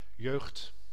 Ääntäminen
Ääntäminen : IPA: [jøːɣt] Tuntematon aksentti: IPA: /ˈjøːxt/ IPA: /jø:ɣt/ Haettu sana löytyi näillä lähdekielillä: hollanti Käännös Ääninäyte Substantiivit 1. jeunesse {f} France Suku: f .